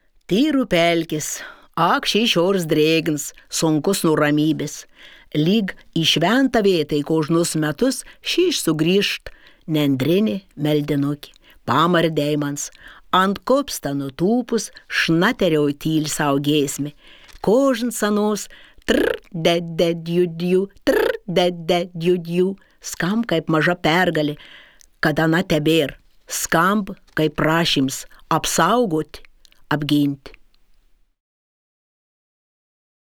• Klausykitės paukščių garsų su ausinėmis.
24-Pauksciai.wav